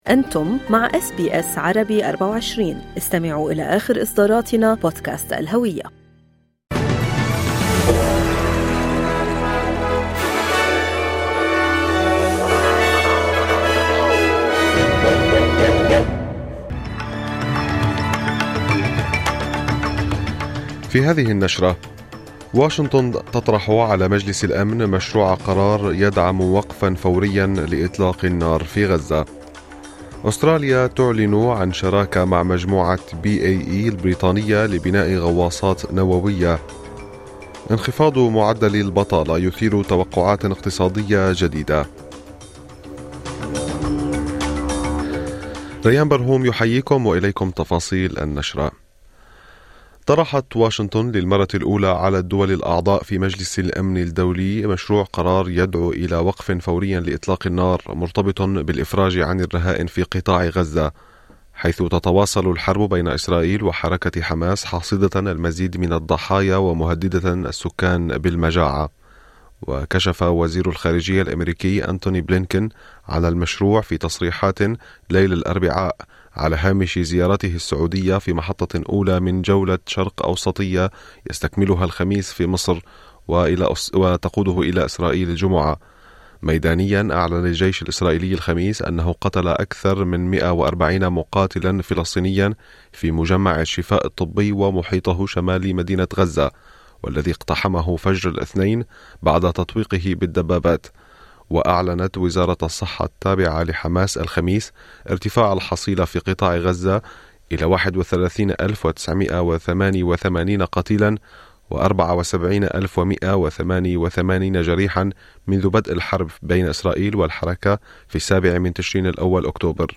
نشرة أخبار الصباح 22/3/2024